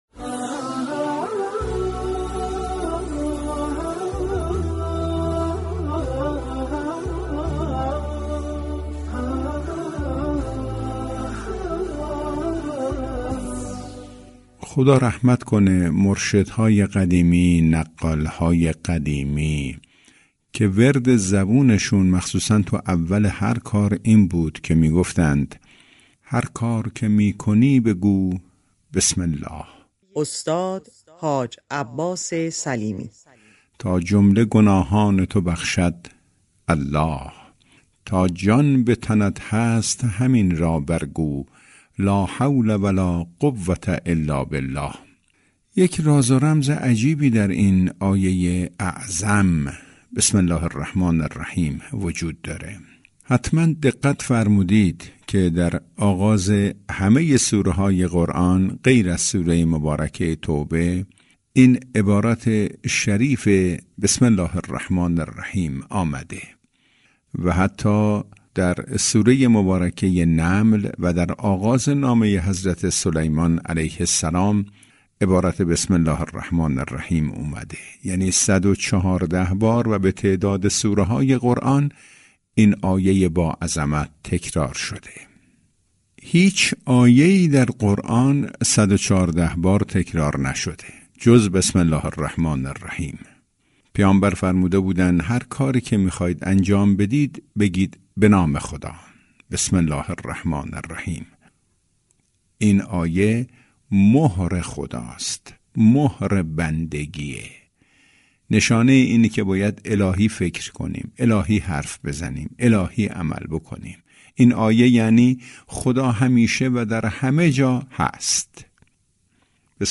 «طعم عسل» برنامه‌ای كوتاه و روزانه است كه با نگاهی اجتماعی، در كمتر از دو دقیقه، مفاهیم عمیق قرآن كریم را به زبان ساده به سبك زندگی امروز پیوند می‌زند و هر روز از رادیو قرآن پخش می‌شود.